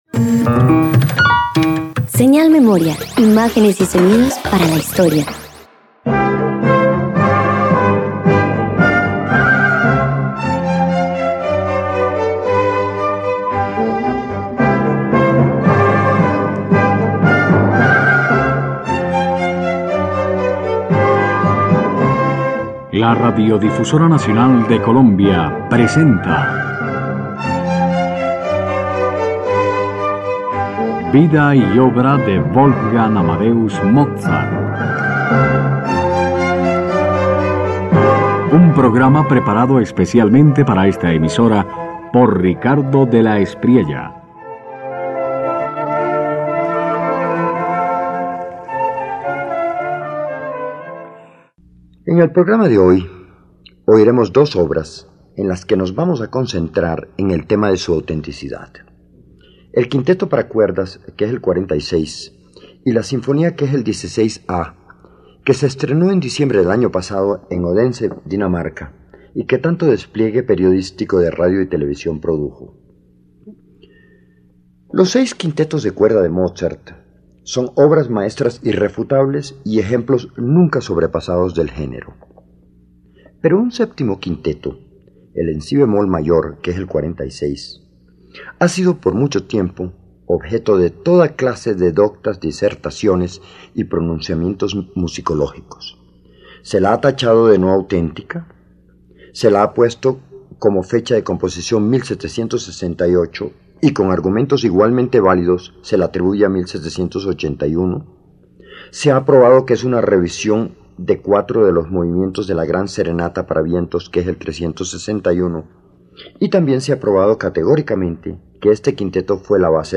No busca exhibir una voz, sino equilibrarlas con cuidado.
Quinteto para cuerdas